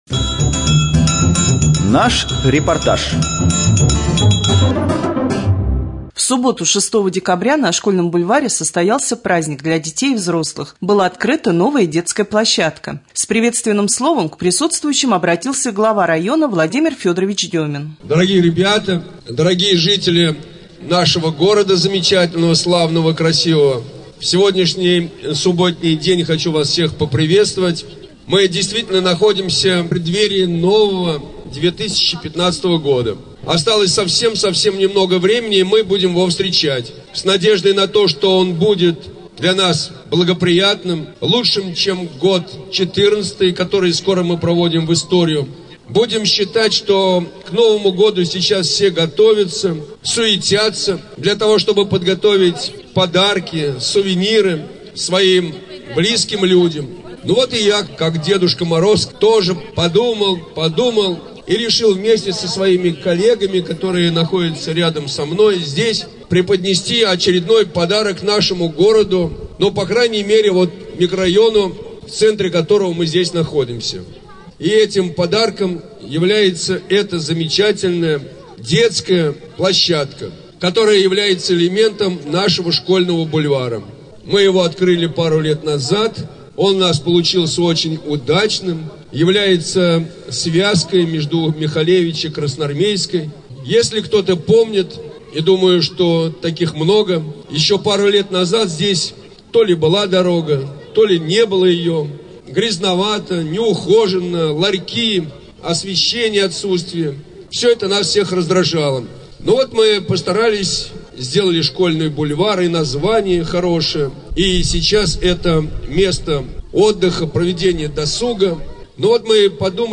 3.Рубрика «Специальный репортаж». Новая детская площадка открыта на Школьном бульваре.